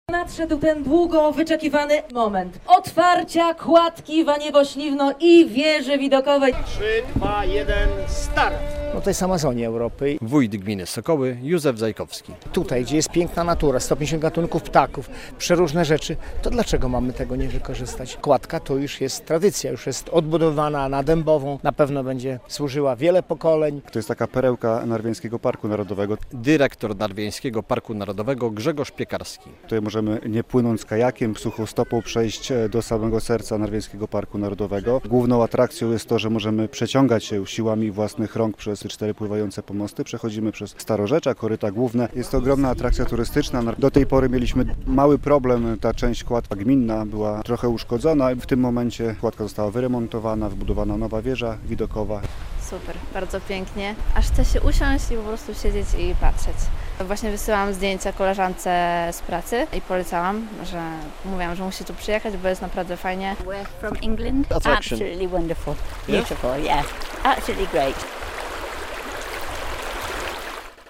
Wiadomości - Kładka Śliwno-Waniewo otwarta po remoncie
relacja